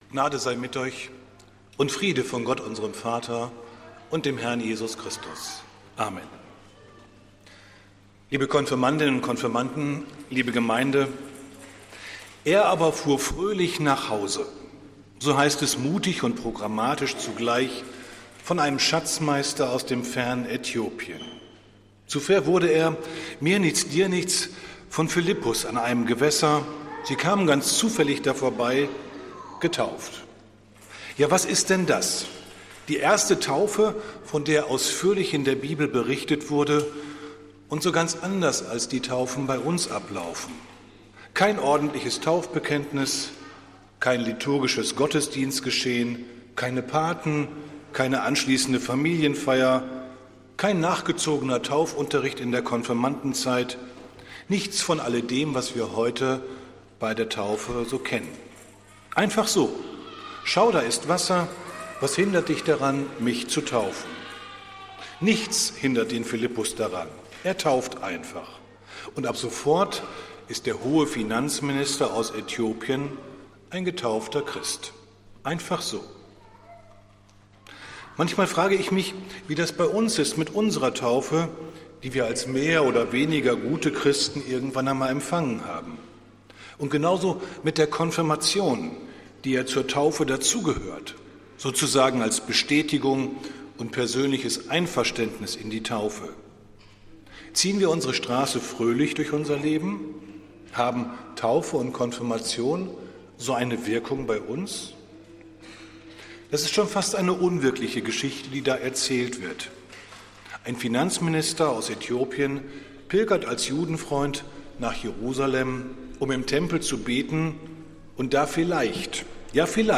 Predigt des Gottesdienstes aus der Zionskirche am Sonntag, den 21. April 2024